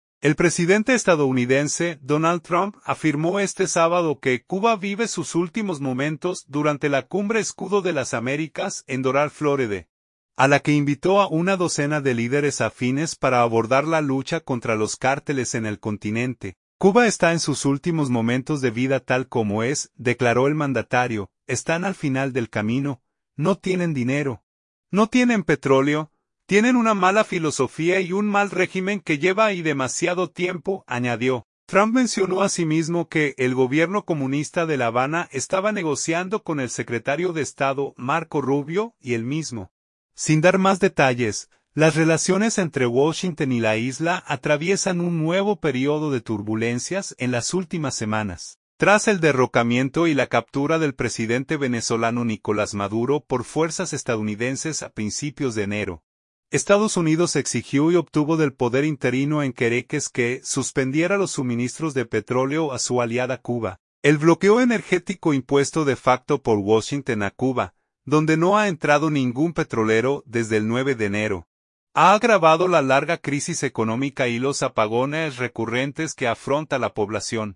El presidente estadounidense, Donald Trump, afirmó este sábado que Cuba "vive sus últimos momentos", durante la cumbre "Escudo de las Américas", en Doral (Florida), a la que invitó a una docena de líderes afines para abordar la lucha contra los cárteles en el continente.